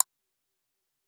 Click.m4a